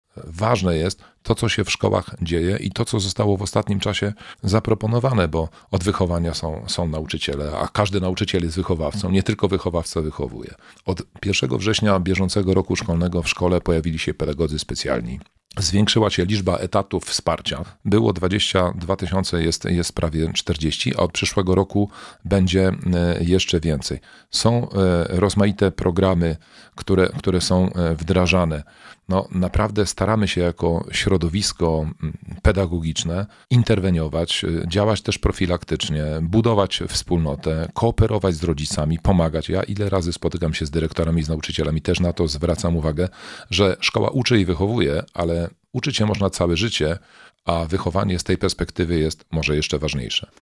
Raport „Otwarcie o zdrowiu psychicznym” przygotowanym przez fundację Martyny Wojciechowskiej – Unaweza komentuje Roman Kowalczyk – Dolnośląski Kurator Oświaty.
Staramy się interweniować, mówi kurator oświaty.